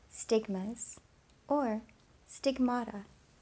stigmas_or_stigmata.mp3